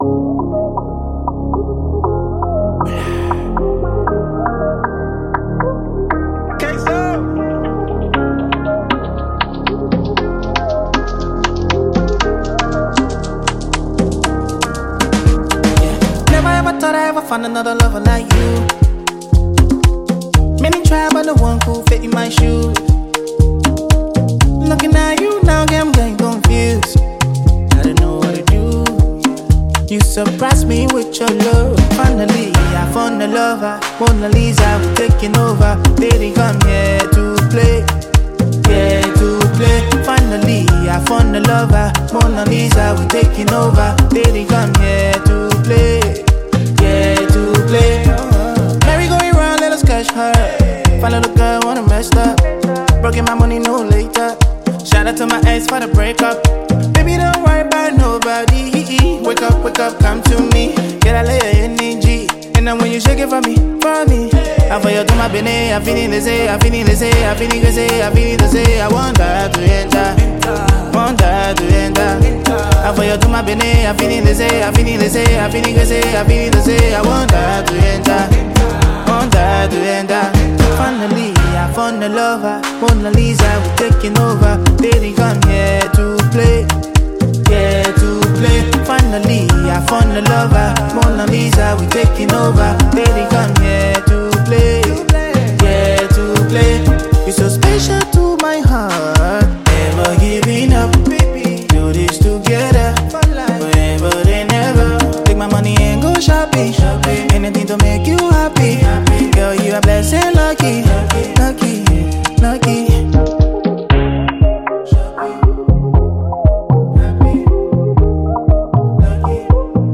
Categories: Afro-Beats,Afro-PopLiberian Music